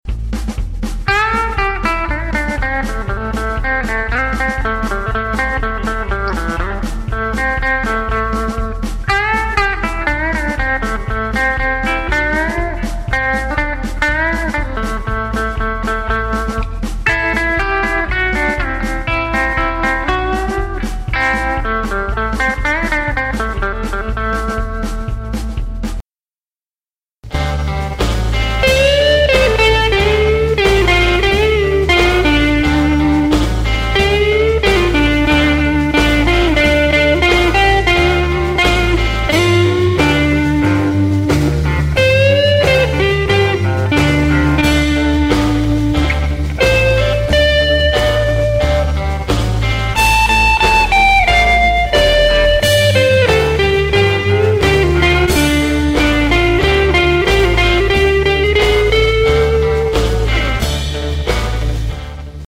The first, which was done with the original Ibanez ACH pickups, is a countrified jam in A with bass and drums in the background. For this clip I used my VAmp 2 with my Bluesy Tremolo patch and the bridge pickup on the guitar to get some twang.
The second, with the ACH neck pickup, is a slow blues tune in D using my 2x12 Crunch patch.